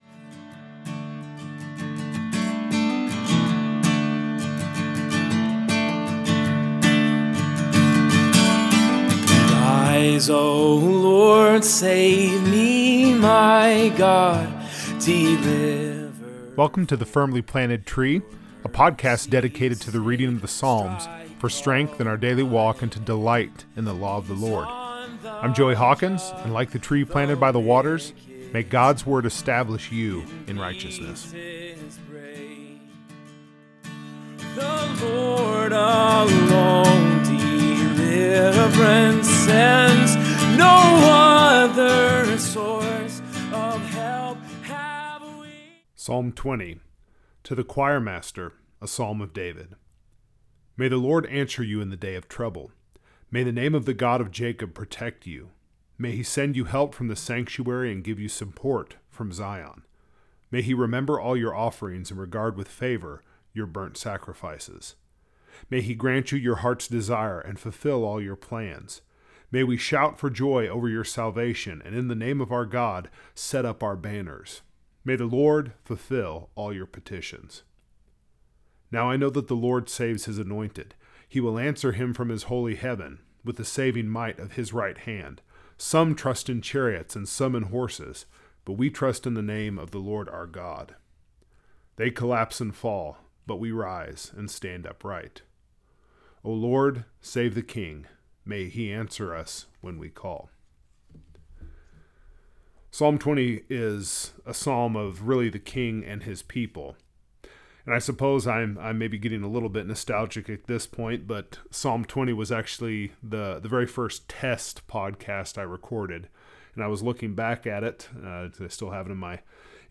In this episode, we read and reflect on Psalm 20, a psalm that is the picture of Godly leaders and those under their care.